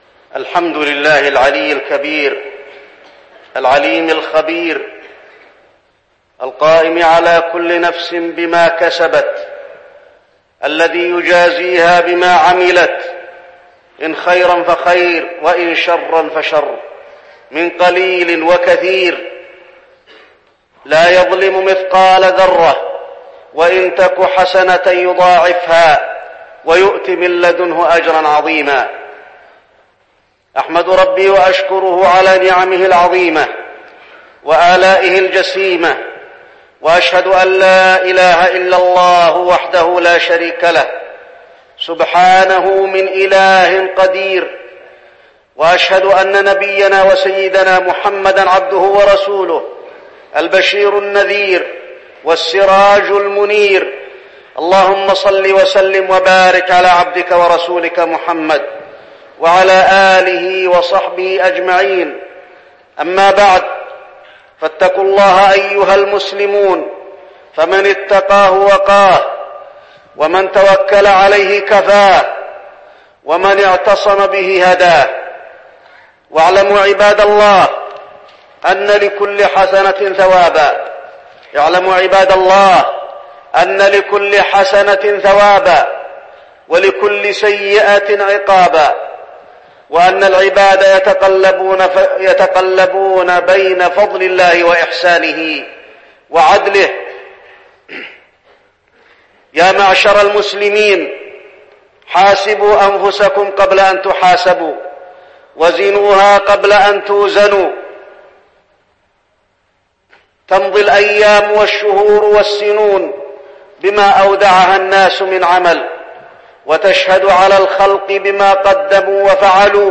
تاريخ النشر ١١ ذو الحجة ١٤١٧ هـ المكان: المسجد النبوي الشيخ: فضيلة الشيخ د. علي بن عبدالرحمن الحذيفي فضيلة الشيخ د. علي بن عبدالرحمن الحذيفي السكنى والتمسك بالمدينة